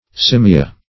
Search Result for " simia" : The Collaborative International Dictionary of English v.0.48: Simia \Sim"i*a\, n. [L., an ape; cf. simus flatnosed, snub-nosed, Gr.